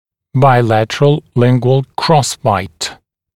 [baɪ’lætərəl ‘lɪŋgwəl ˈkrɔsbaɪt][бай’лэтэрэл ‘лингуэл ˈкросбайт]двусторонний лингвальный перекрестный прикус